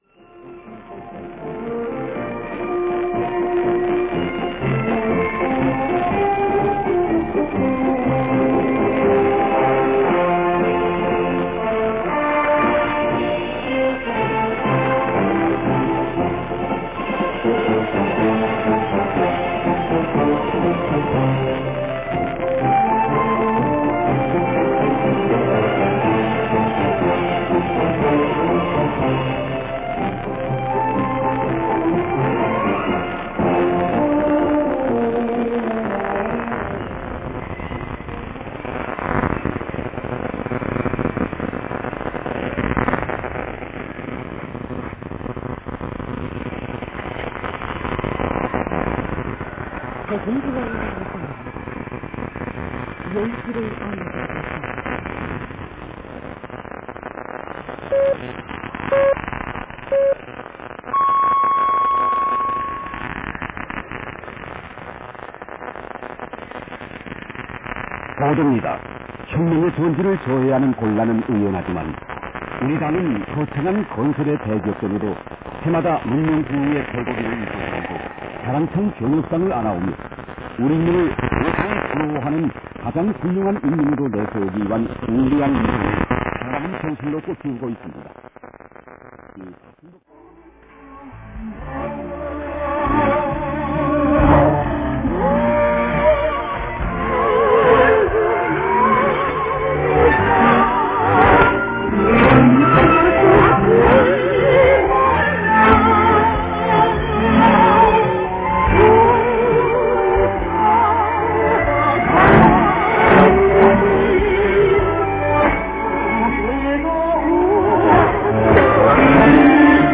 15日の日中11680kHz、夜間3250, 720kHzを野外受信チェックをしました。
<11680kHz 受信地：東京都北区赤羽 荒川河川敷 RX:ICF-SW7600GR ANT:Built-in whip>
<3250, 720kHz 受信地：東京都江東区新砂 東京湾荒川河口 RX:ICF-SW7600GR ANT:AN-12>
※00:50-00:55 女性アナ時報アナウンス「朝鮮中央放送イムニダ。ヨルシ(10時)ルル アルリョドゥリムニダ 」
※04:50-04:55 女性アナ時報アナウンス「朝鮮中央放送イムニダ。スムラナシ(21時)ルル アルリョドゥリムニダ 」